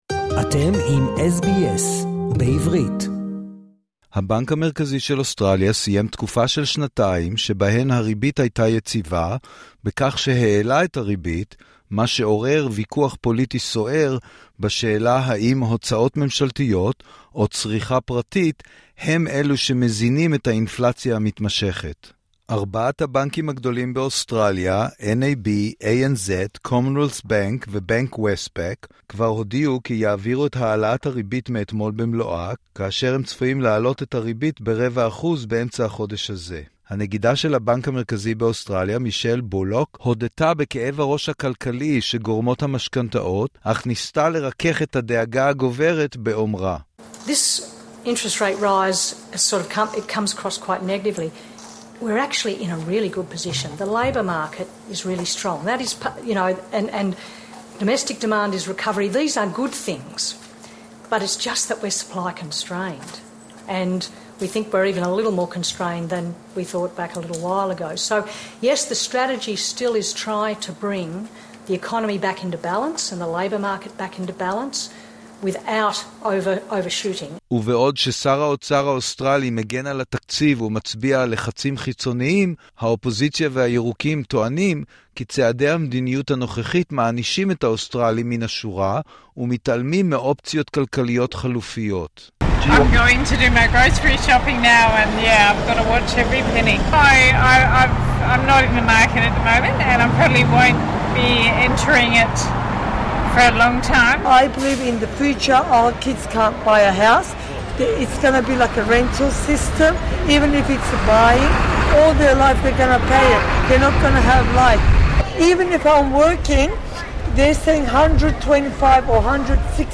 הבנק המרכזי של אוסטרליה סיים תקופה של שנתיים שבהן הריבית הייתה יציבה בכך שהעלה את הריבית, מה שעורר ויכוח פוליטי סוער בשאלה האם הוצאות ממשלתיות או צריכה פרטית הם אלו מזינים את האינפלציה המתמשכת. SBS בעברית שוחח עם שני מומחים פיננסיים מהקהילה, במטרה להבין טוב יותר את השפעת העלאת הריבית האחרונה, ומה יכולים האוסטרלים לצפות לו בחודשים הקרובים.